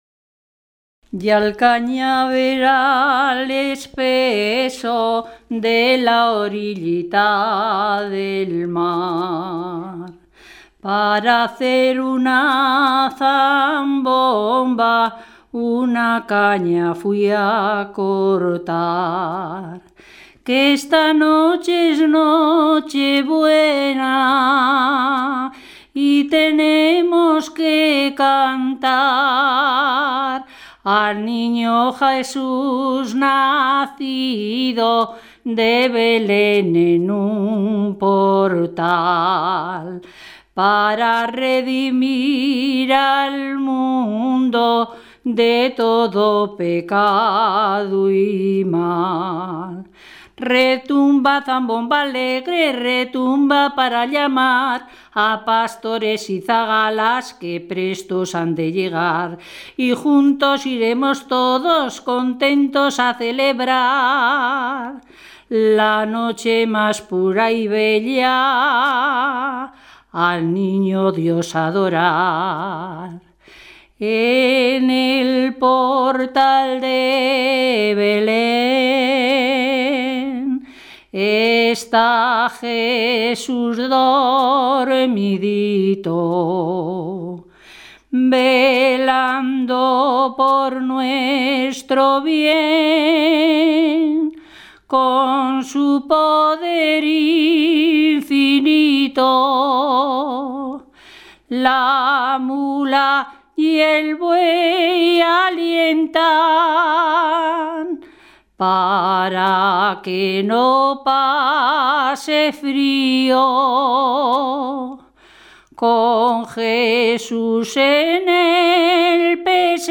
Clasificación: Cancionero
Localidad: Aguilar del Río Alhama
Lugar y fecha de recogida: Aguilar del Río Alhama, 30 de septiembre de 2001
Se trata de un villancico de origen culto.
en labios de una mujer